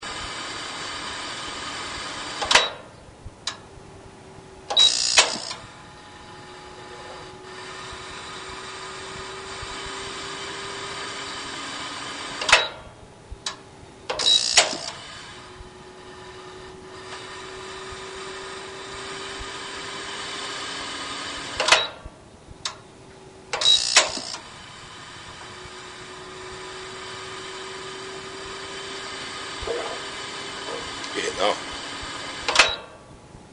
FW-3301消化、再点火の繰り返しの音　160kB
dainichi-fw-3301-saitenka.mp3